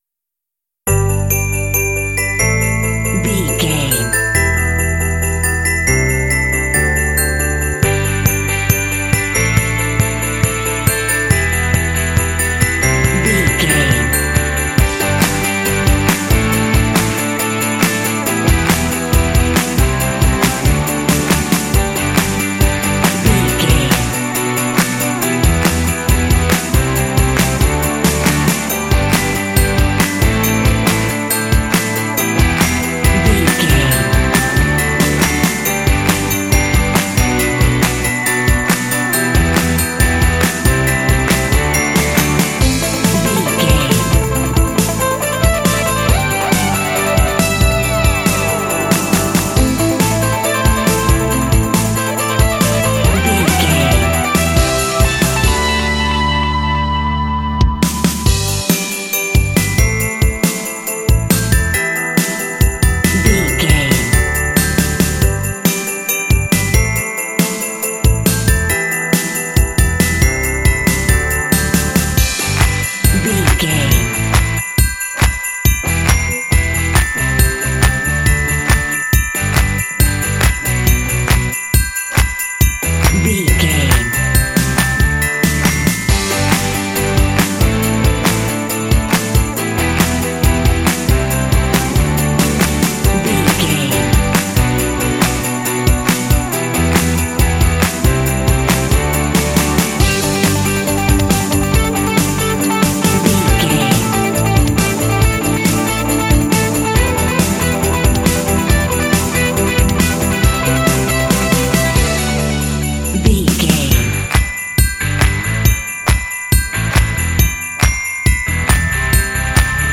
Ionian/Major
bouncy
festive
drums
electric guitar
bass guitar
contemporary underscore